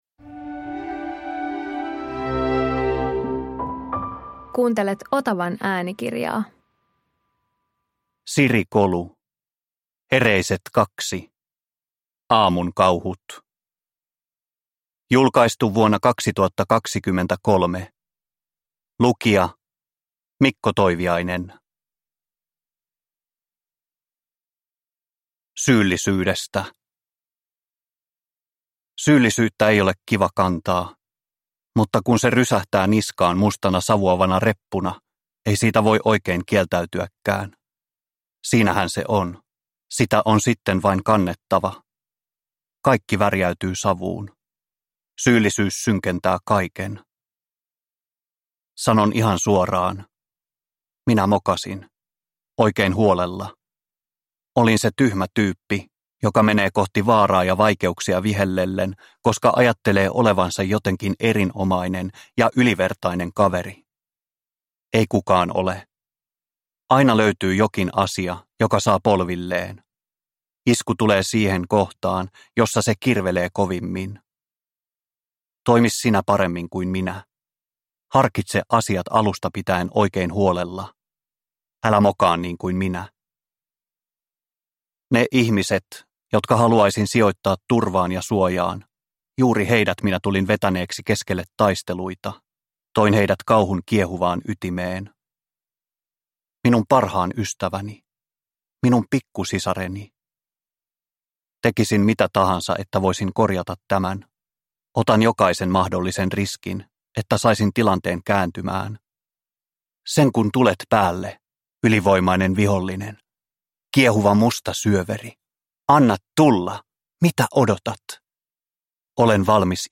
Hereiset - Aamun kauhut – Ljudbok – Laddas ner